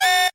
alarm small 01